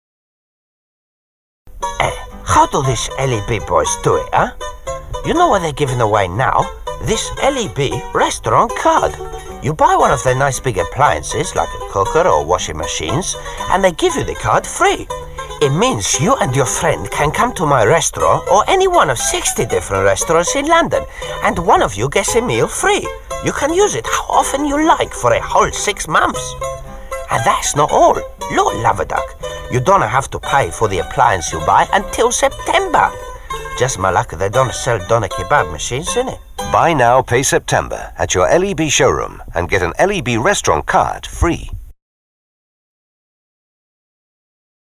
Electricity Board Radio Ad